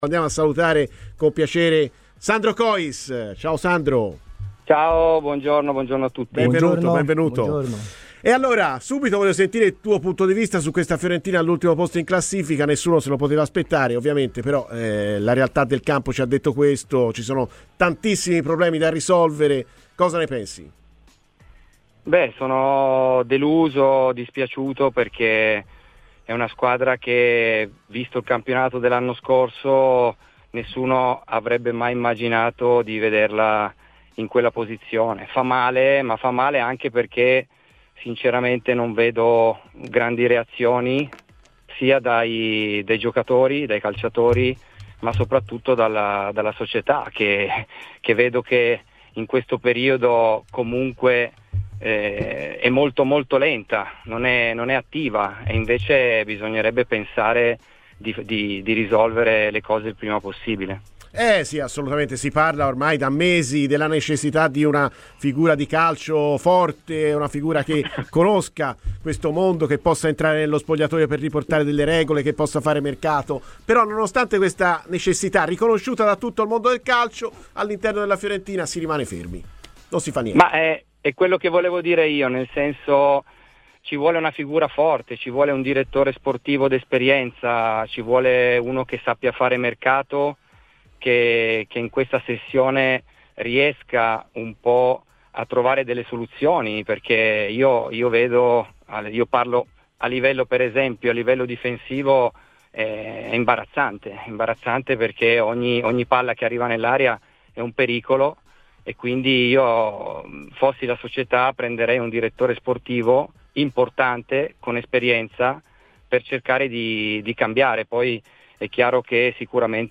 Ascolta il podcast l'intervista completa!